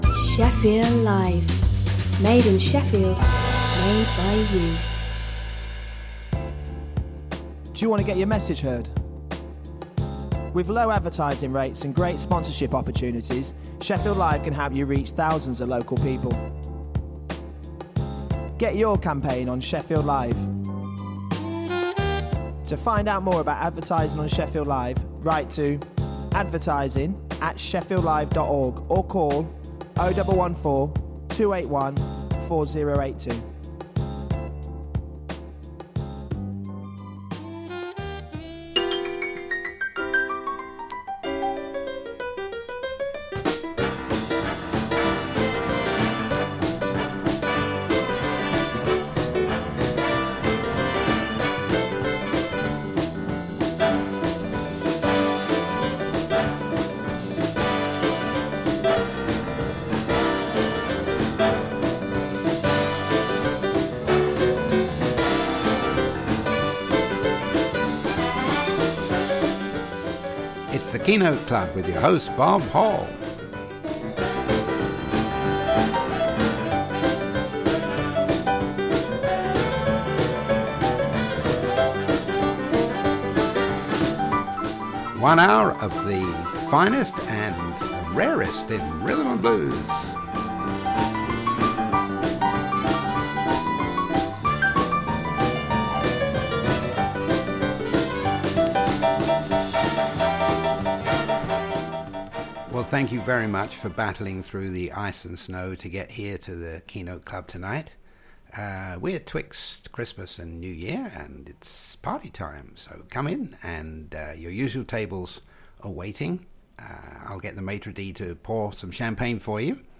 Shefffield Live presents… One hour of the best and the rarest in blues and rhythm ‘n’ blues